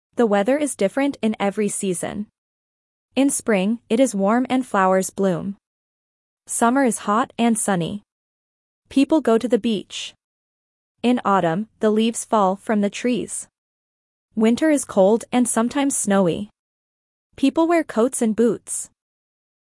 Dictation A2 - Weather and Seasons